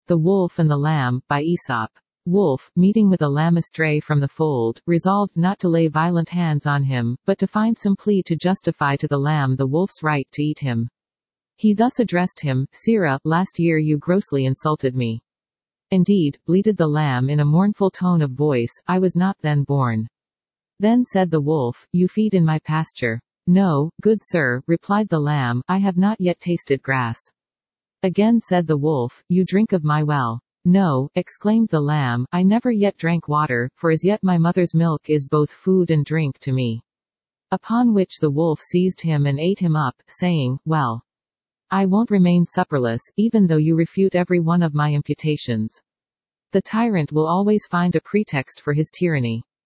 Standard (Female)